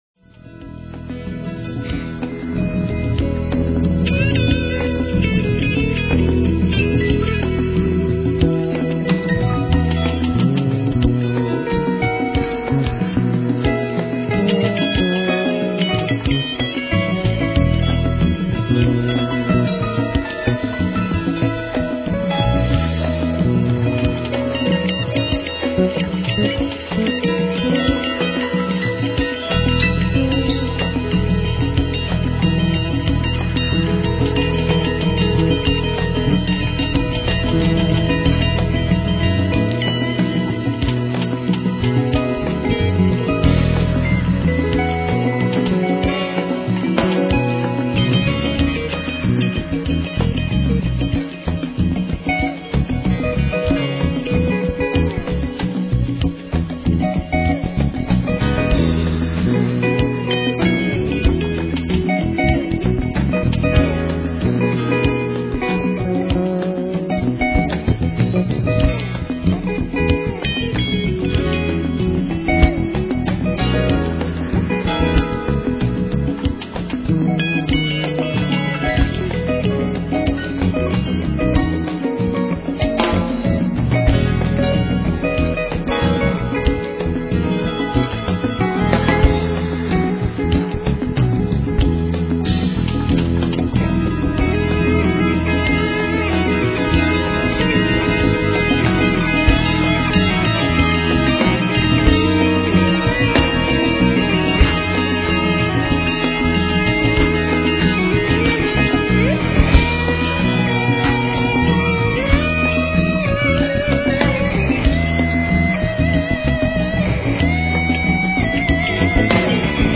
recorded at Midtown Recording